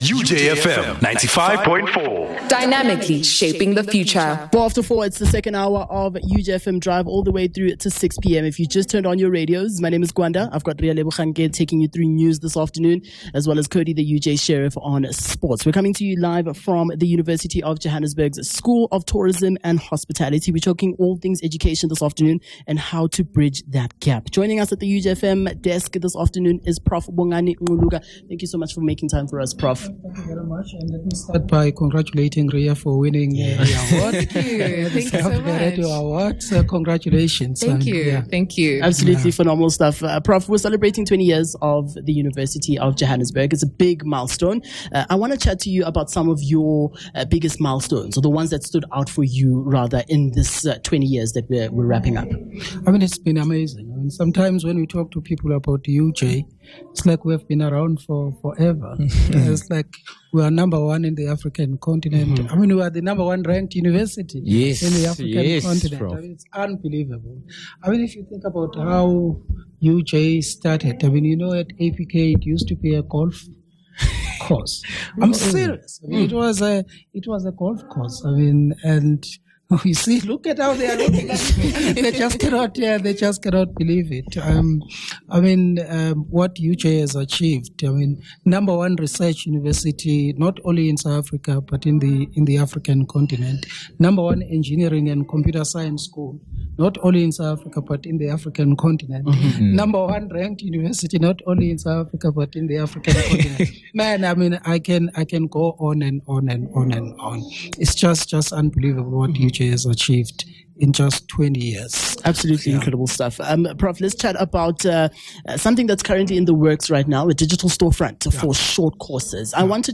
17 Jan UJ Hosting The Department of Basic Education - Interview